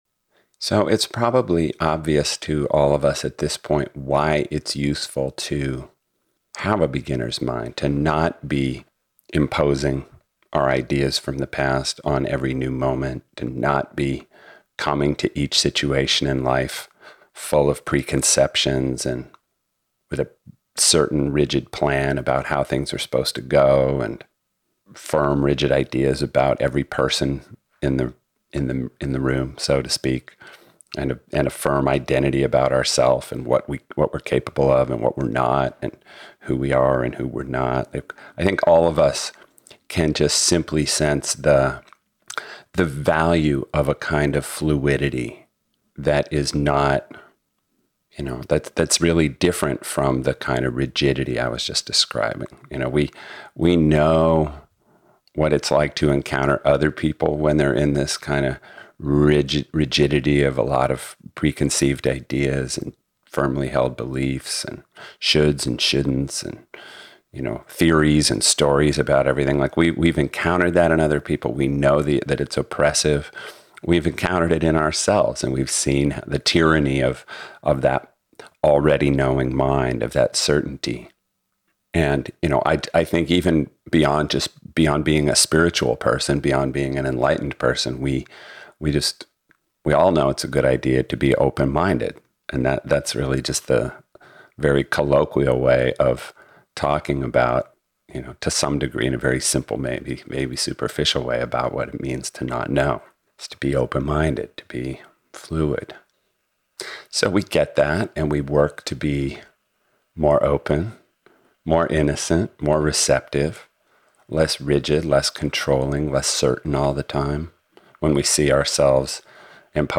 FREE AUDIO SEMINAR